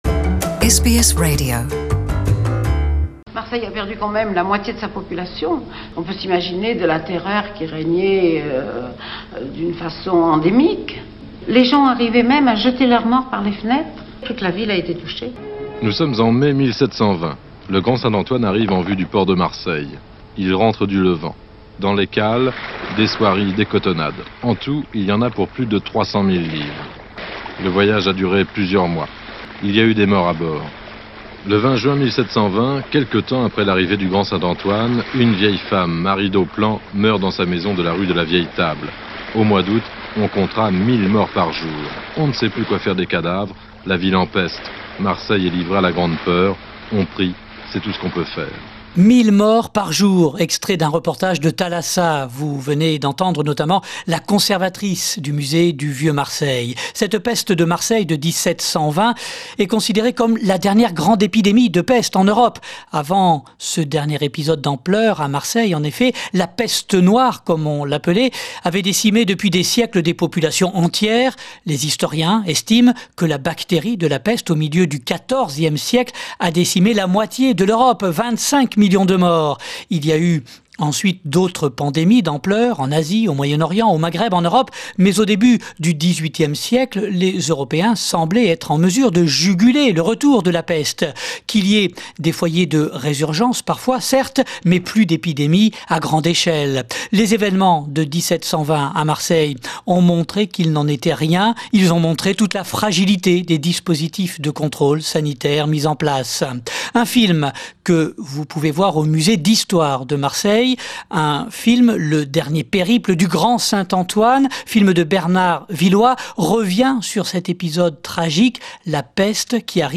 Retour avec les archives sonores de l’INA sur cette grande peste de Marseille.